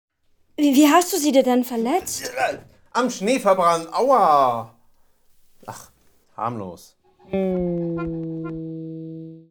Sprecher-Demos
Mehrere Ausschnitte aus dem Hörspiel „Schnee“ (2024) nach Texten von Jorinde Minna Markert.
Gesprochene Rolle: „Kurt“.